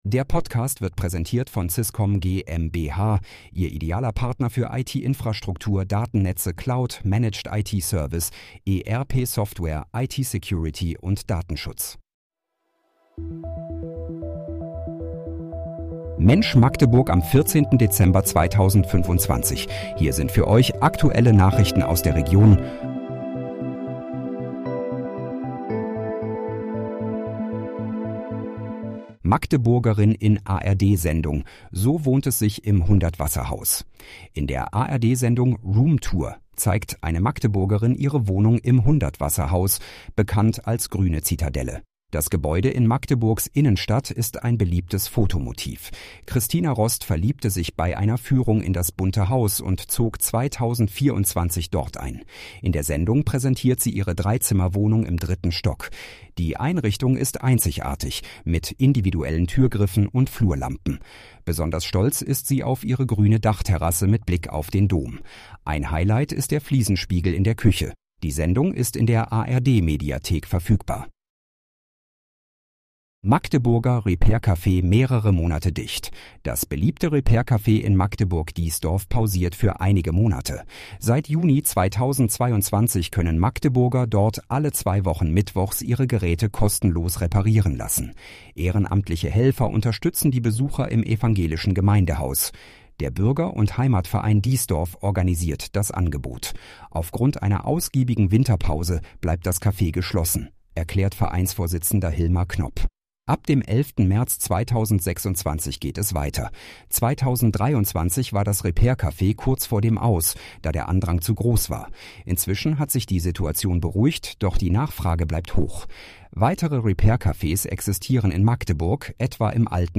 Mensch, Magdeburg: Aktuelle Nachrichten vom 14.12.2025, erstellt mit KI-Unterstützung
Nachrichten